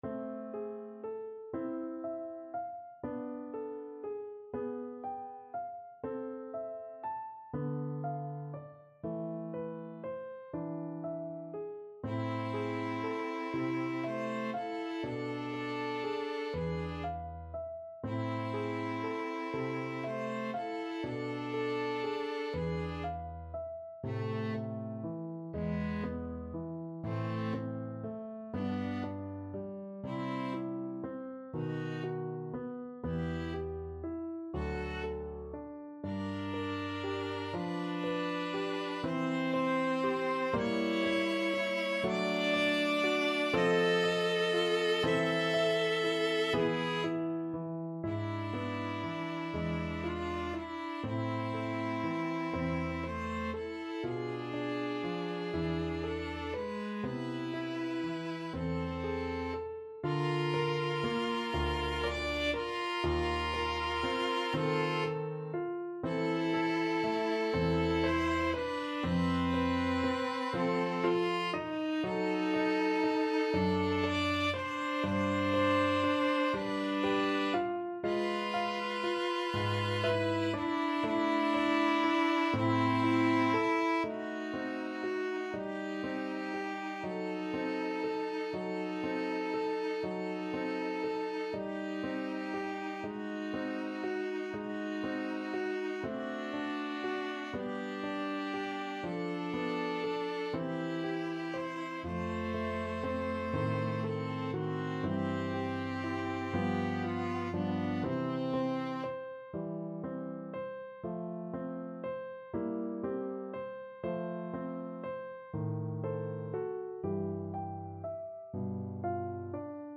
12/8 (View more 12/8 Music)
= 120 Larghetto